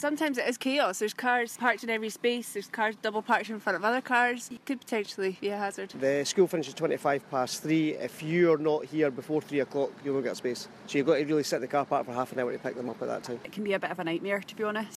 LISTEN: Parents give their views on parking outside Kingsland Primary in Peebles